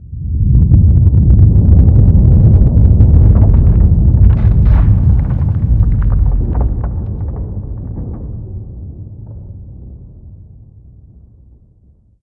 cave13.ogg